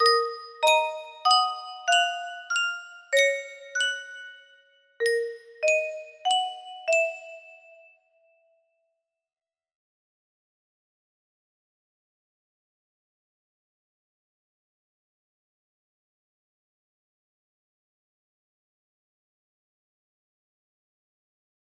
PLasma islet wip music box melody
Full range 60